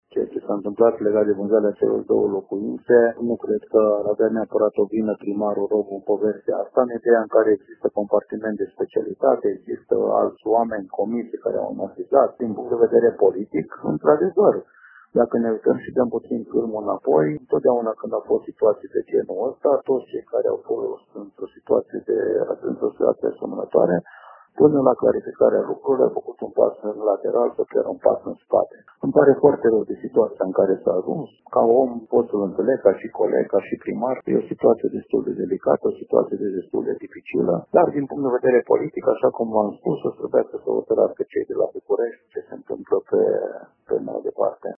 Primarul din Sânnicolau Mare, Dănuț Groza.